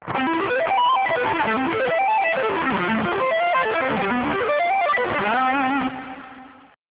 Advanced Sweep Picking
Sweeping of 7th chord arpeggios is also a great way to use them to give it a more jazzy flavor.
Below is an example using the generic I, VI, IV, V progression again.